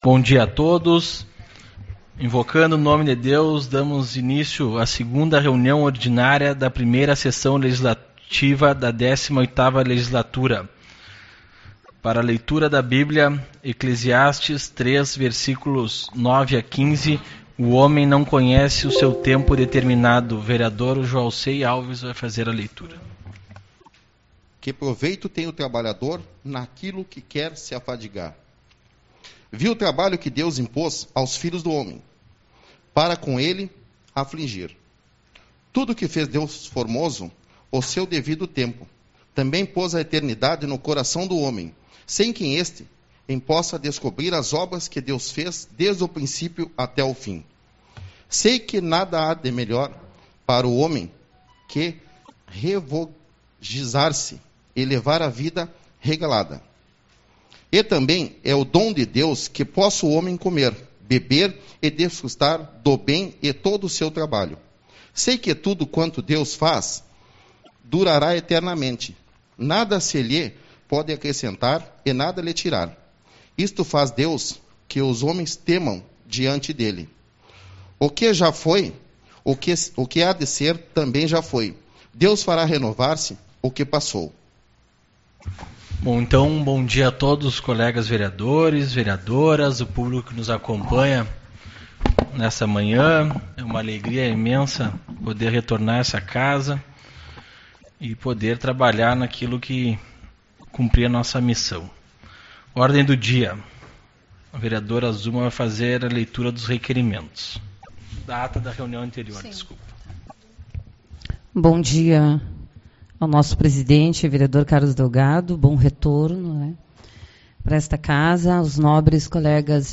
04/02 - Reunião Ordinária